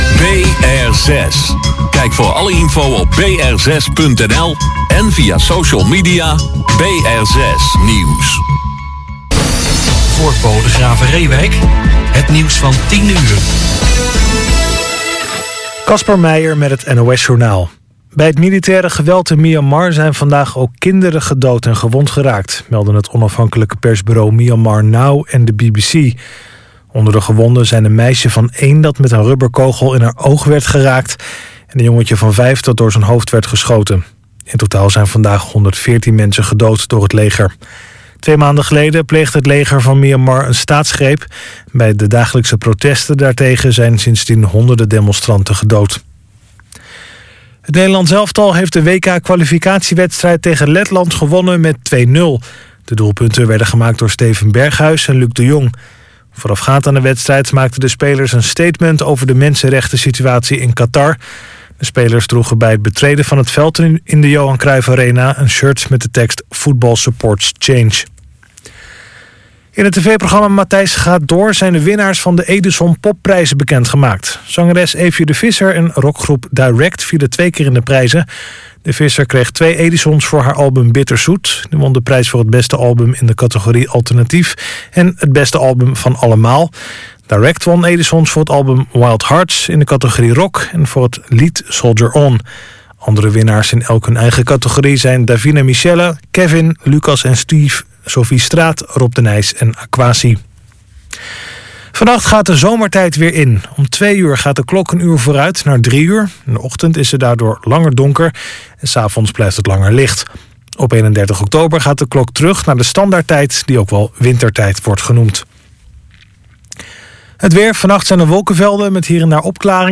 “Riverside Jazz” wordt elke zaterdagavond uitgezonden via BR6, van 22:00 tot 00:00 uur.